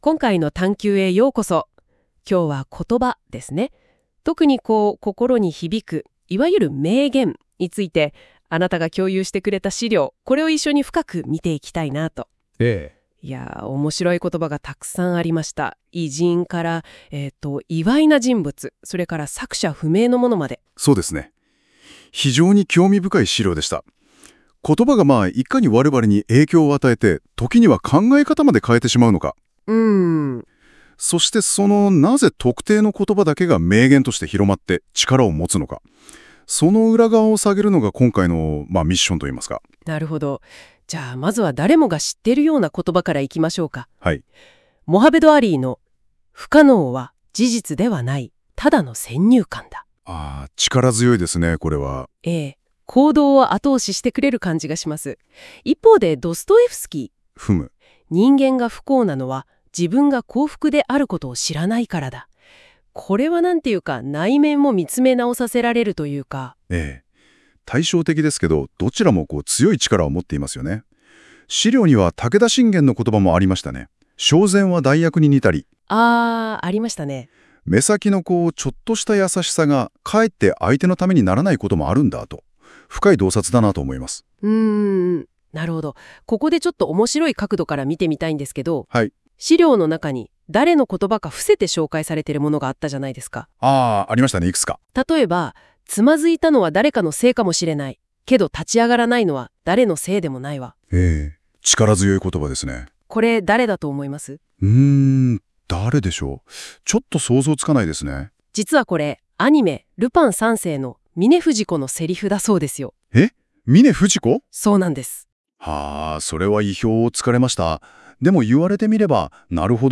なお、今回のコラムの内容も冒頭で紹介したNotebookLMに音声にしてもらいました。